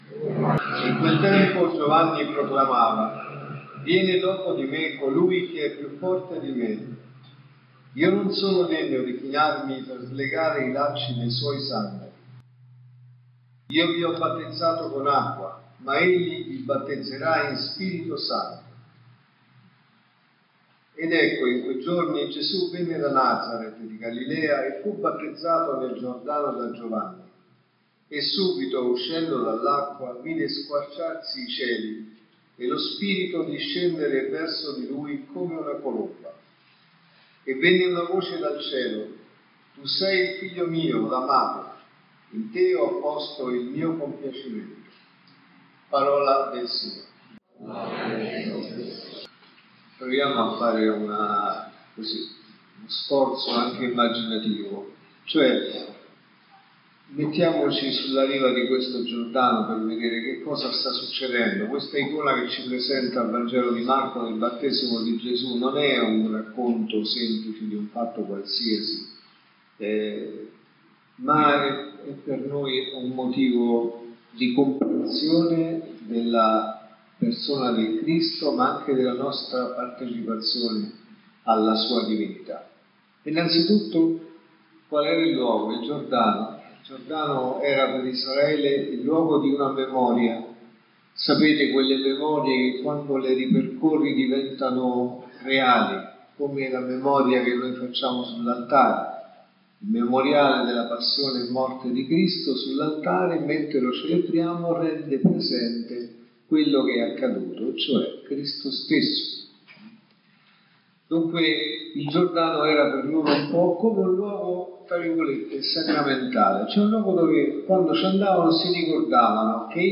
L’omelia può essere ascoltata sul canale…